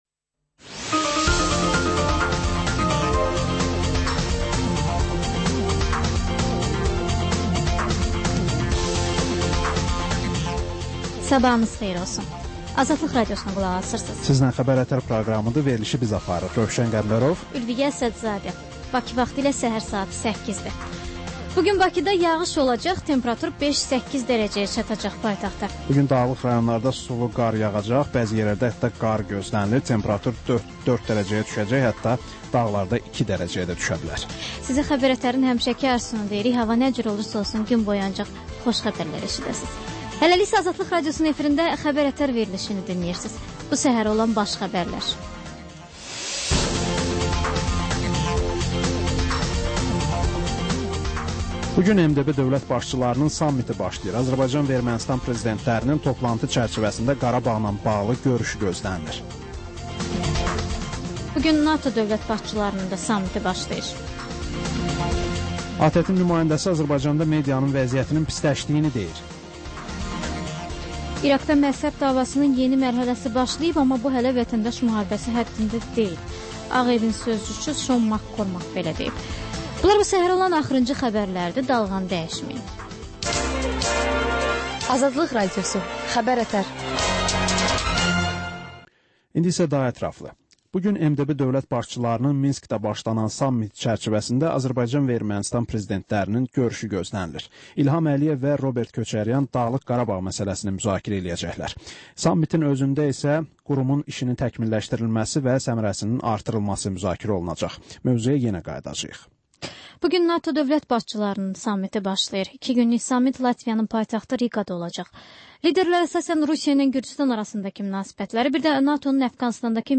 Səhər-səhər, Xəbər-ətər: xəbərlər, reportajlar, müsahibələr. Hadisələrin müzakirəsi, təhlillər, xüsusi reportajlar. Və sonda: Azərbaycan Şəkilləri: Rayonlardan reportajlar.